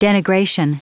Prononciation audio / Fichier audio de DENIGRATION en anglais
Prononciation du mot denigration en anglais (fichier audio)